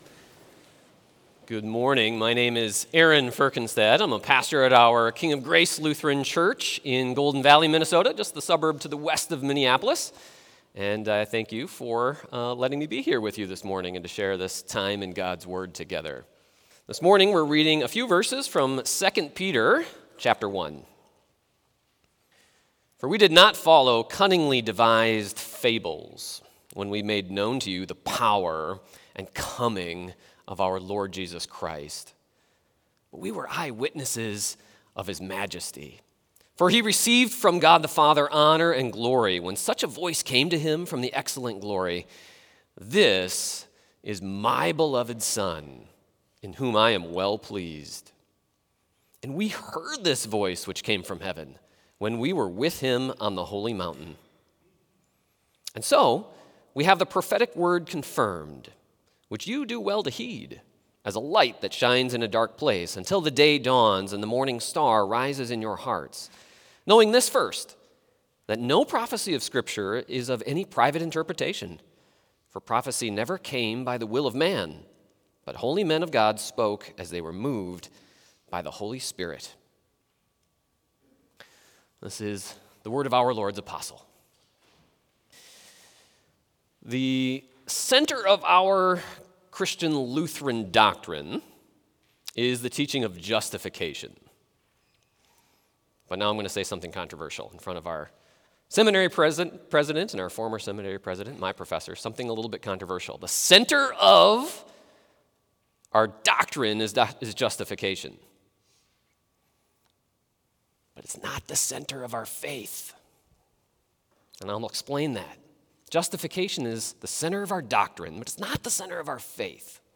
Complete service audio for Chapel - Tuesday, February 11, 2025